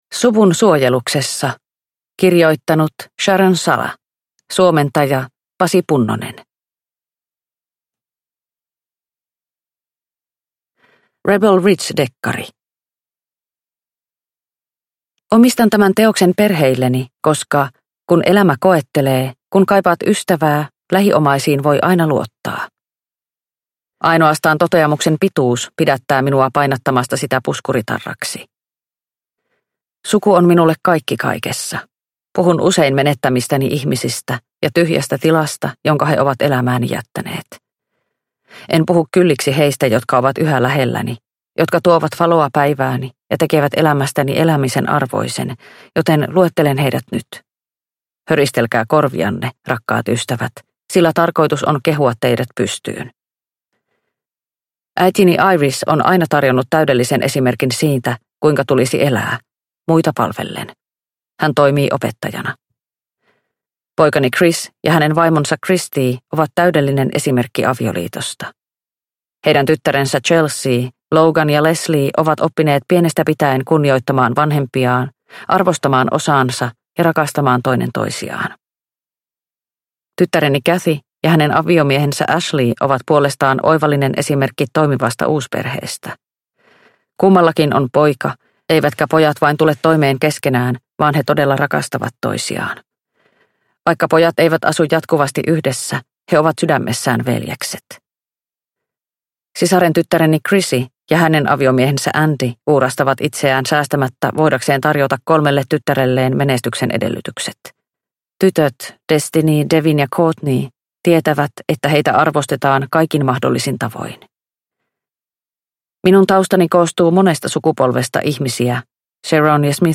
Suvun suojeluksessa – Ljudbok – Laddas ner